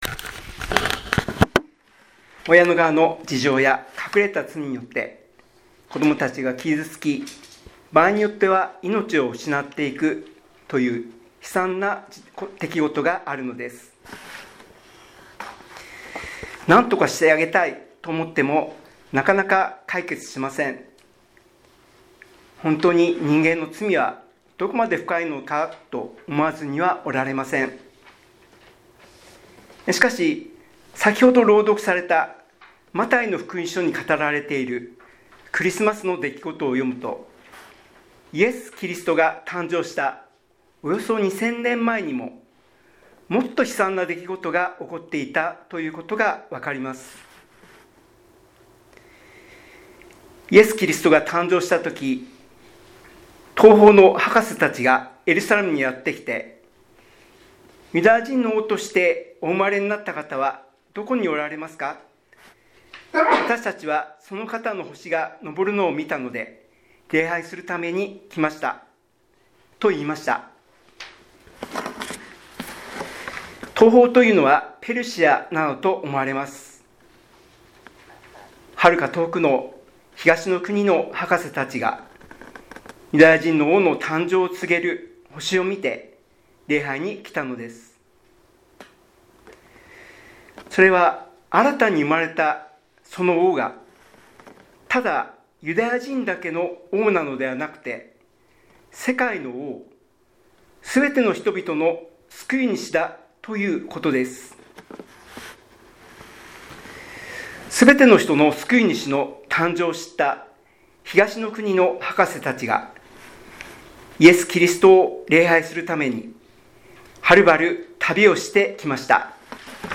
2025年12月24日クリスマス礼拝
礼拝メッセージ